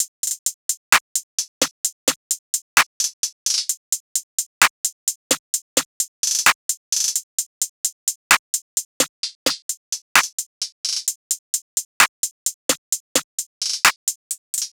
SOUTHSIDE_beat_loop_cut_top_01_130.wav